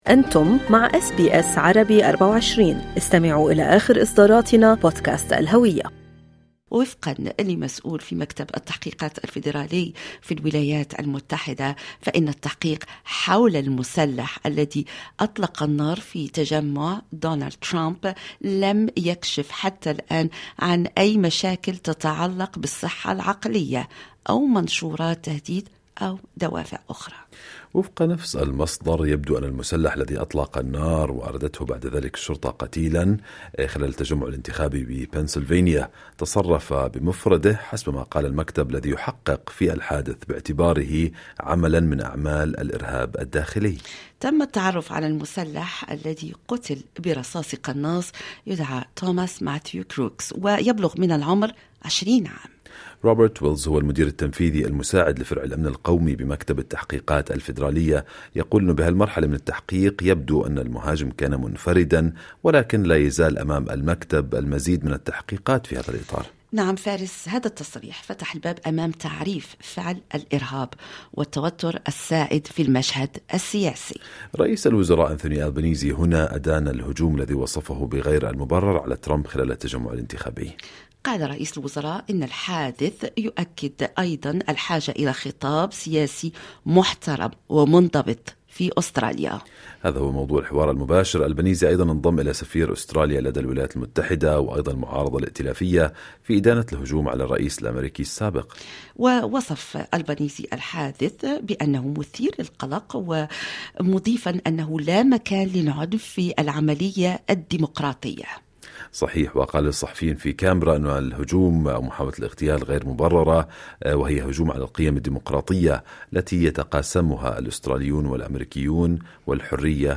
تحدثنا مع الخبير في قضايا الأمن القومي ومكافحة الإرهاب،